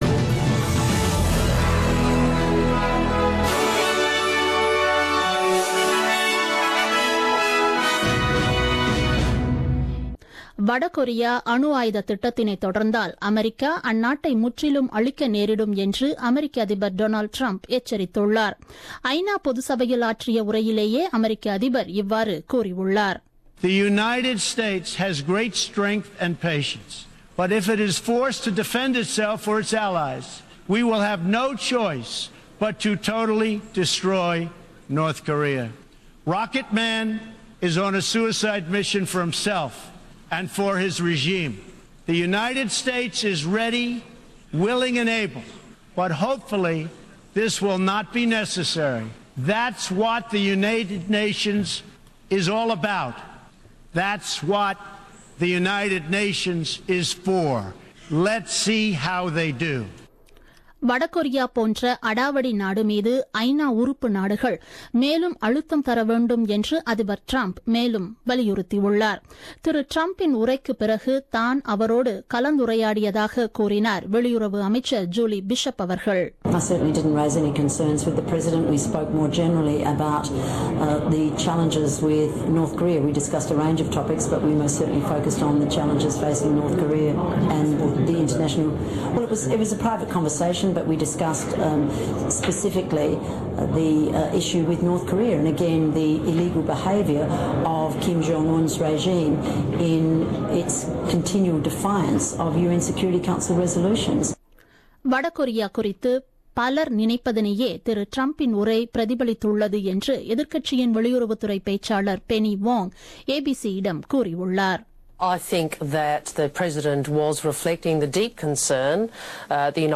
The news bulletin broadcasted on 20th September 2017 at 8pm.